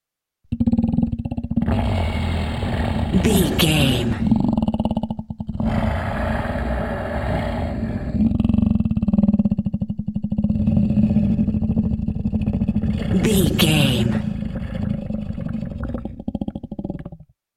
Monster growl snarl predator
Sound Effects
scary
ominous
angry